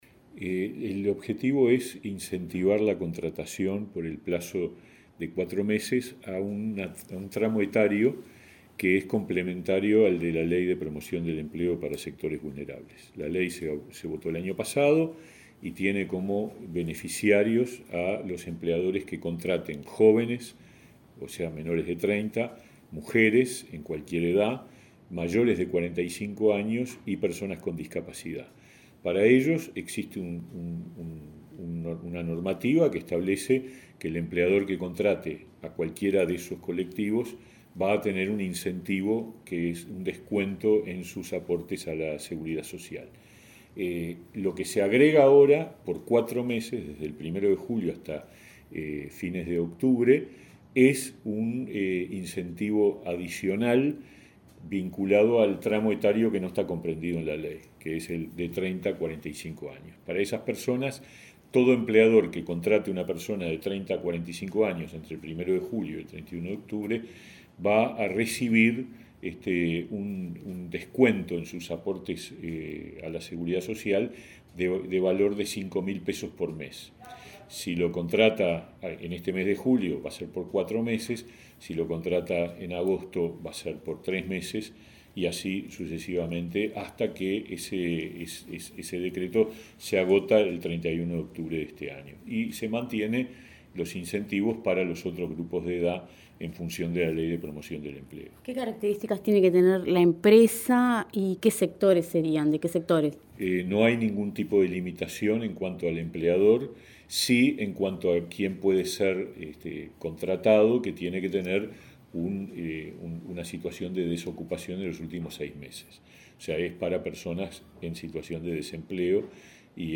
Entrevista al ministro de Trabajo, Pablo Mieres | Presidencia Uruguay